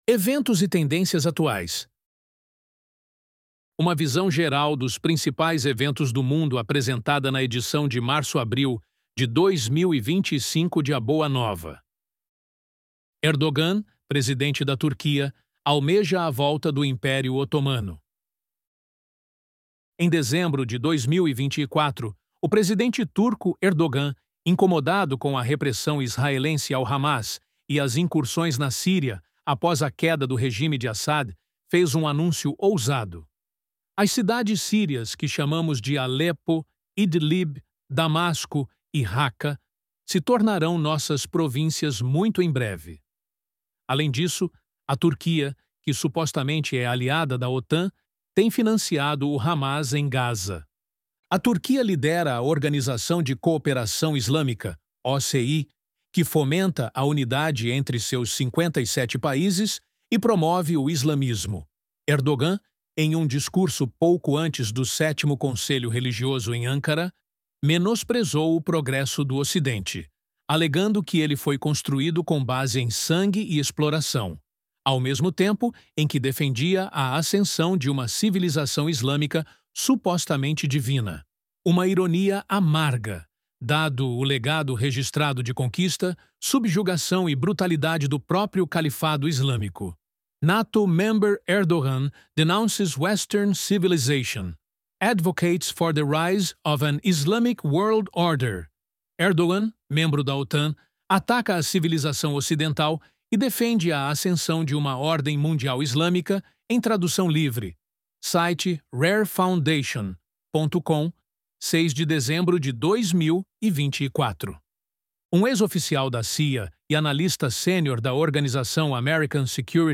ElevenLabs_Eventos_e_Tendências_Atuais.mp3